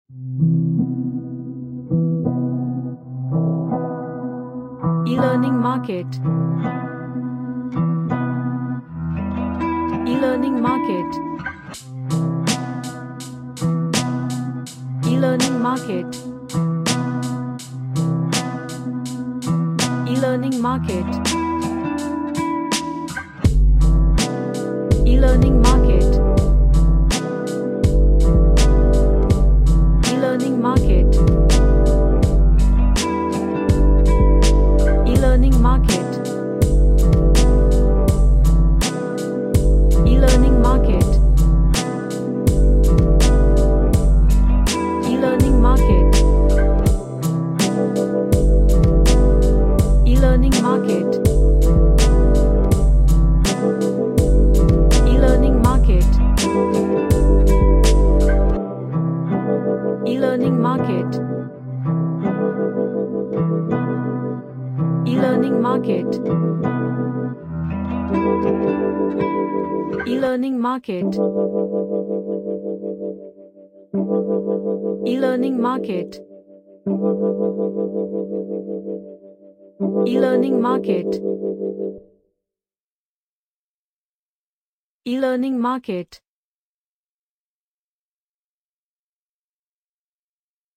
An atmoshpheric ambient lofi track